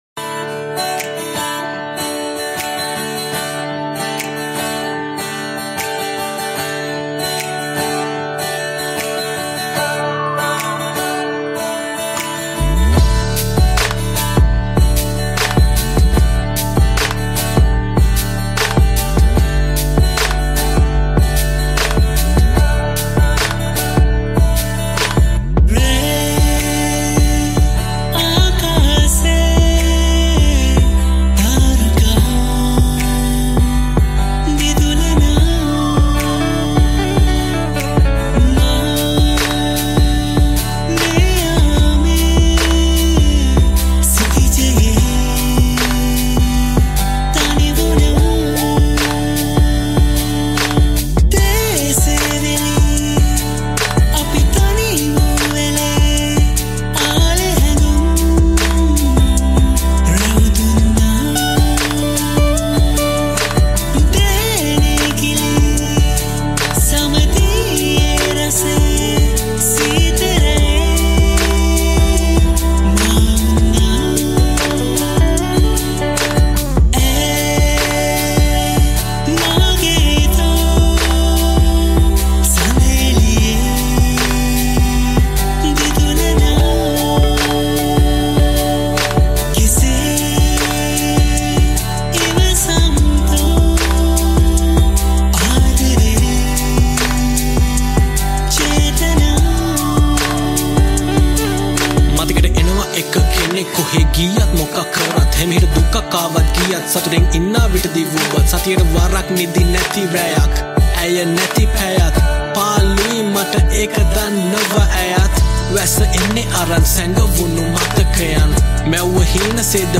Guitar
Harmonies